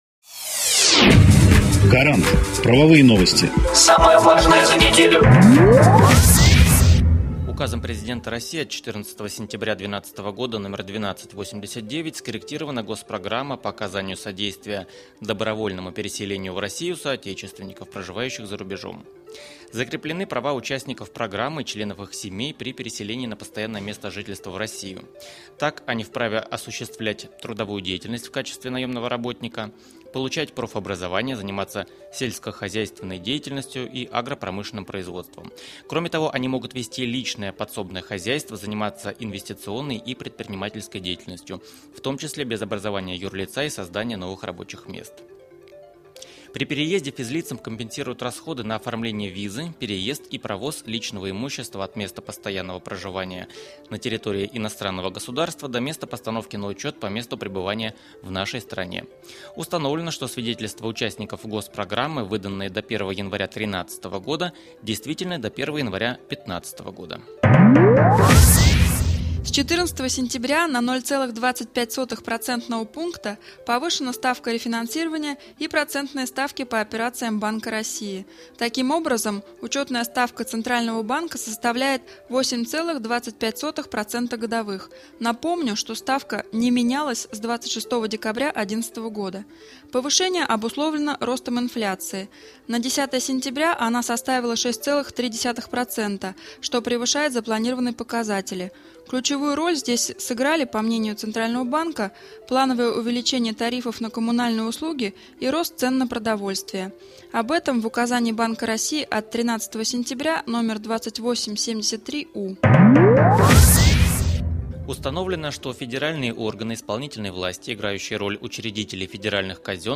Эксперты компании "Гарант" доступно и кратко рассказывают об актуальных законодательных нововведениях за последнюю неделю, акцентируя внимание на самом важном и интересном.